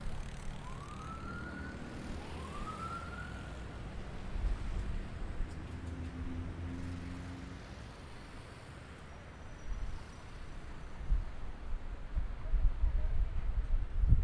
描述：波哥大直布罗陀公共街道（“Avenida 19”）的交通（哥伦比亚）以XY技术（44.1 KHz）排列的Zoom H4录制
Tag: 音景 环境 交通 现场录音 城市